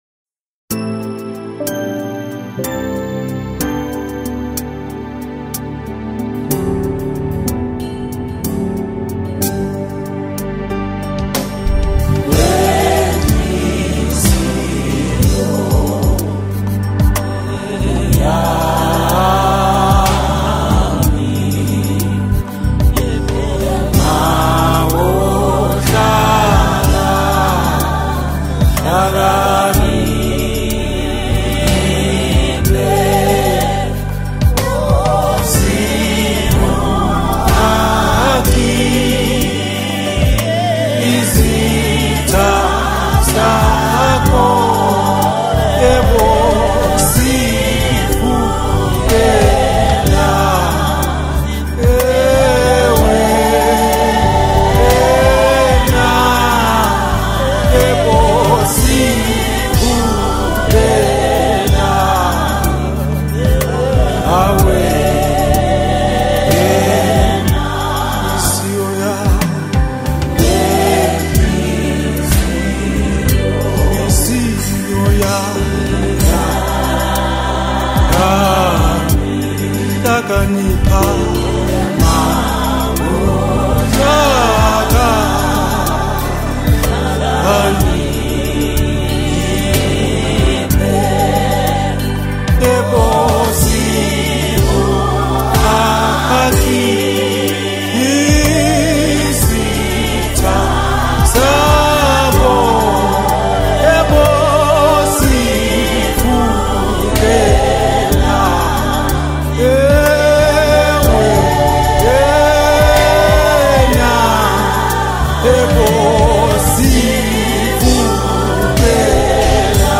Looking for a good gospel music to add to your playlist